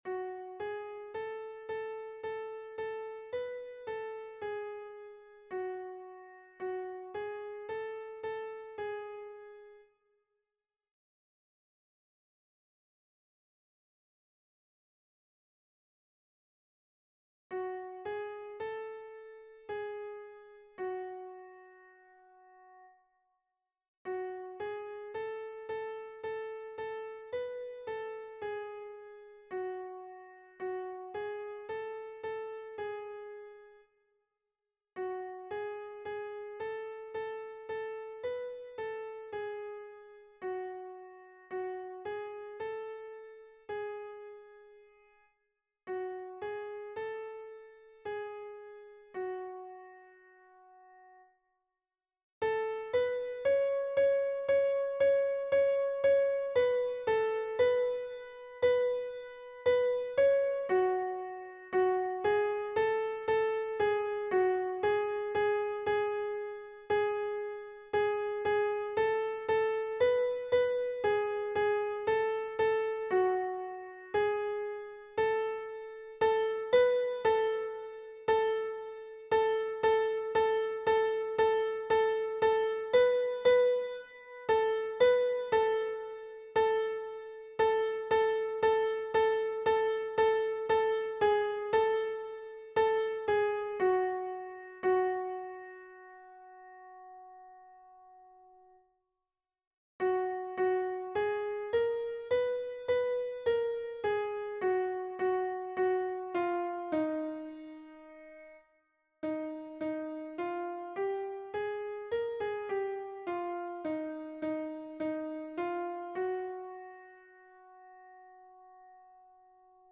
MP3 version piano
Mezzo-soprano